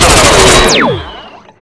rifle3.wav